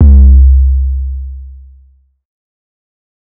Midnight 808.wav